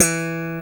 Index of /90_sSampleCDs/East Collexion - Bass S3000/Partition A/SLAP BASS-D